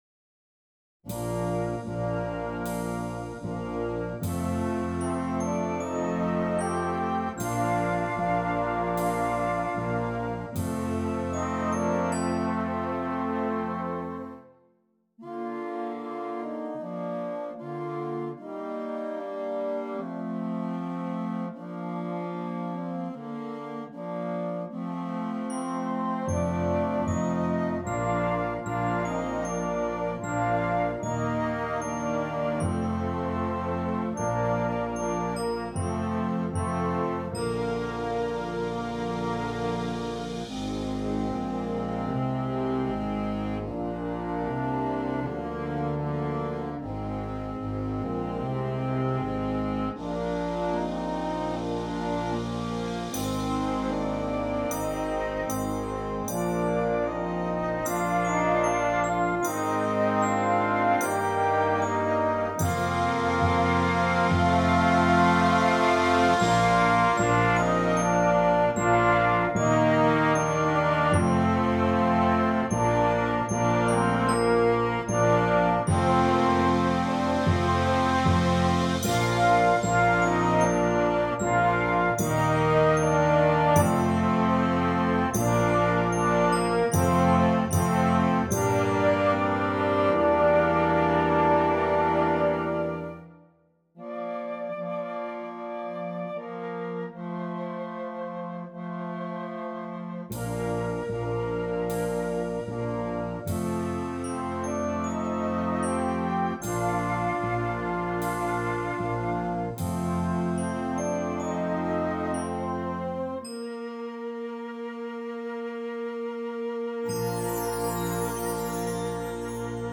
Concert Band
calm, peaceful and simple to perform.